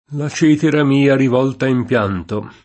©$tera]: la cetera mia rivolta in pianto [